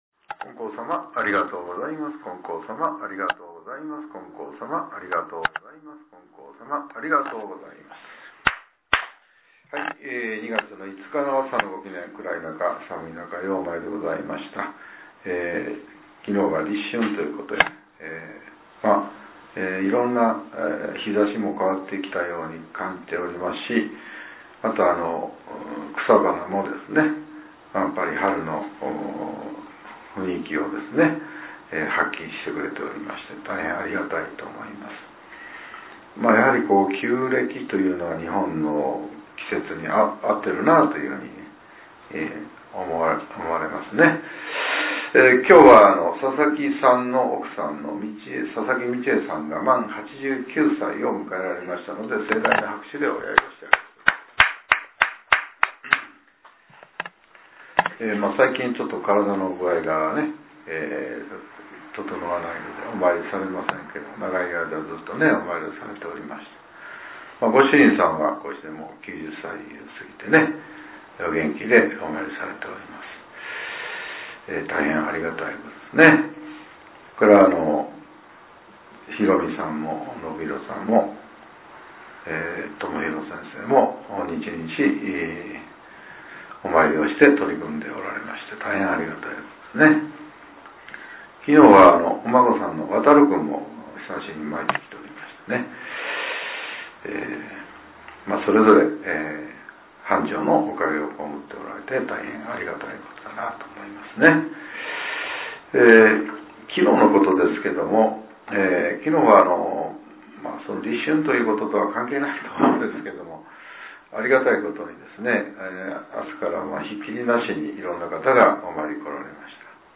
令和８年２月５日（朝）のお話が、音声ブログとして更新させれています。